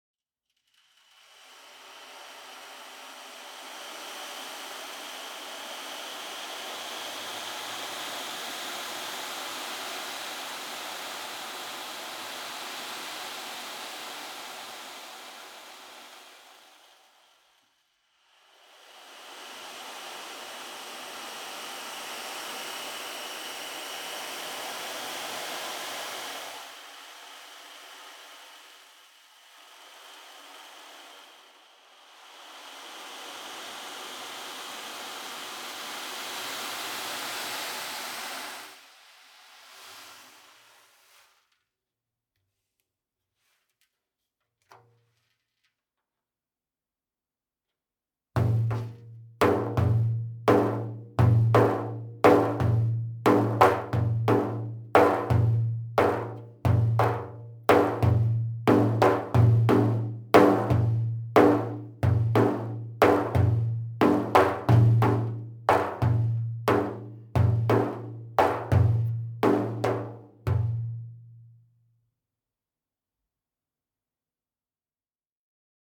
The Meinl Sonic Energy Wave Drums create the soothing sounds of the surf.